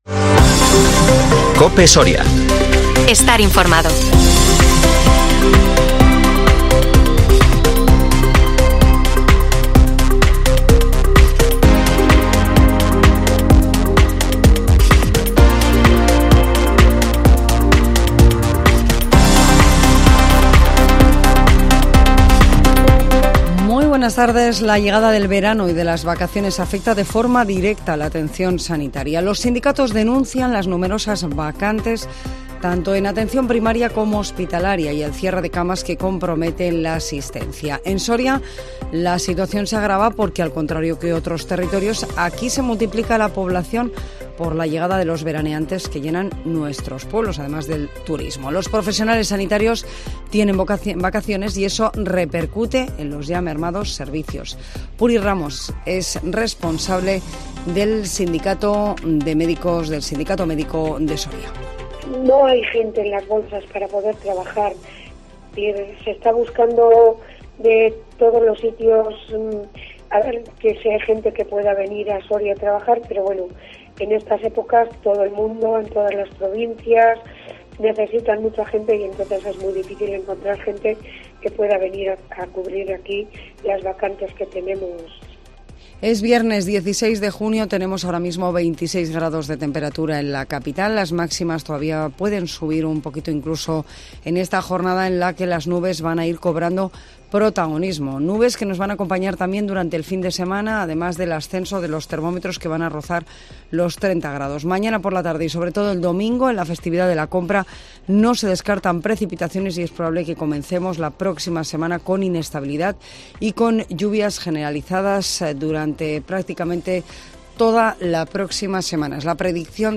INFORMATIVO MEDIODÍA COPE SORIA 16 JUNIO 2023